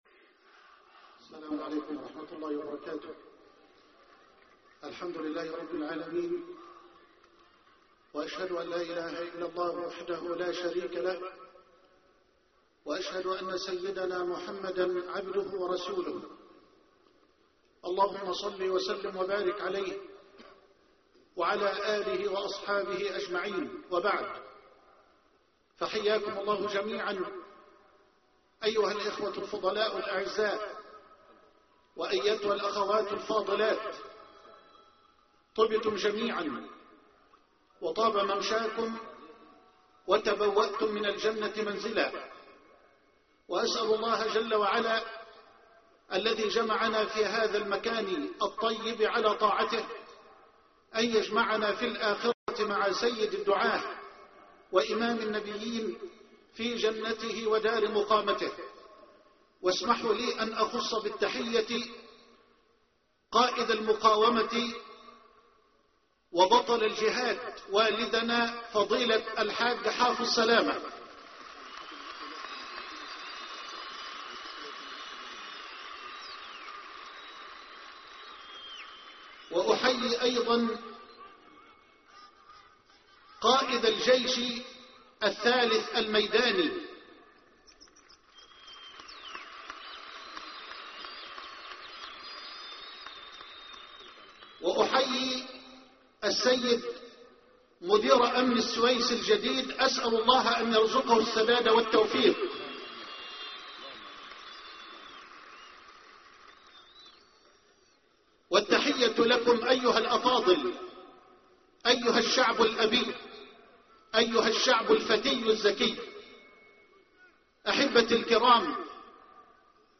مؤتمر معاً لإعادة الثقة بين الشرطة والشعب - مدينة السويس (8/3/2011) - فضيلة الشيخ محمد حسان